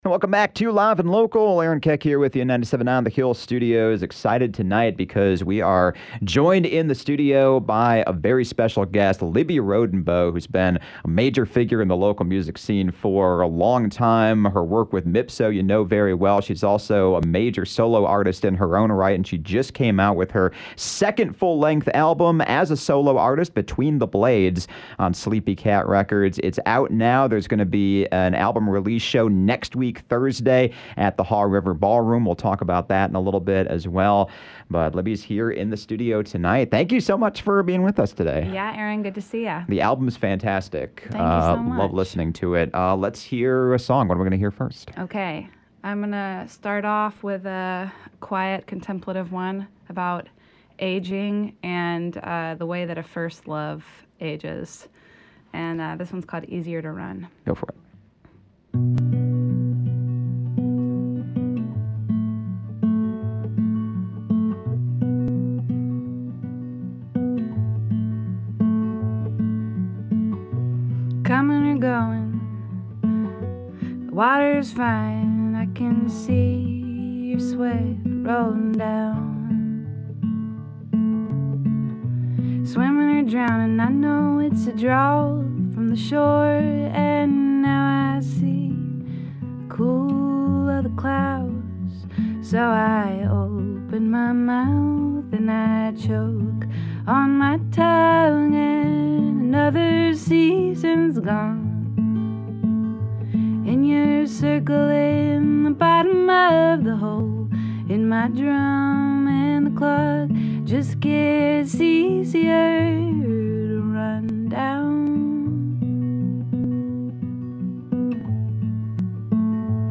to discuss the album and perform three songs live